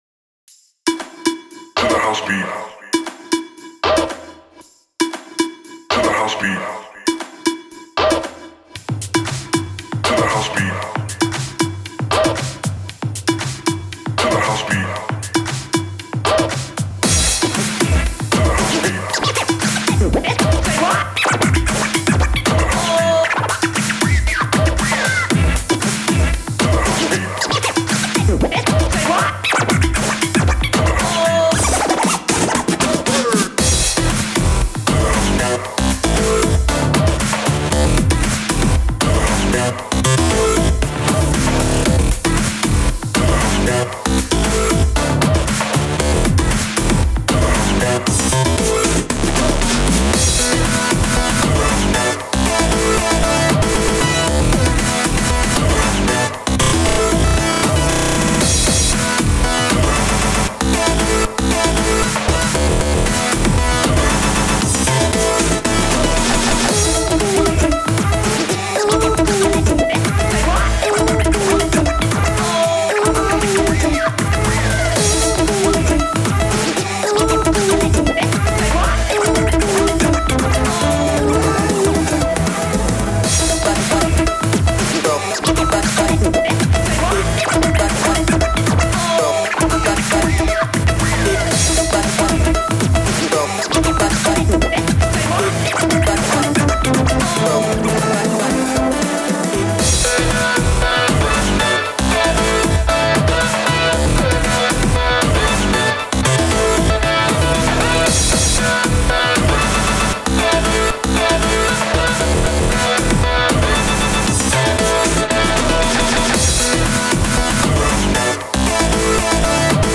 Big beat [+NRG]
今回は今までと変わってスローテンポなテクノでお馴染みBig beatです。
といいつつ、やってることは結構自由気ままでエナジーやDUBの要素も盛り込まれています。
お遊びでターンテーブルを心地よくキメていくのが最近楽しくてたまりません^^。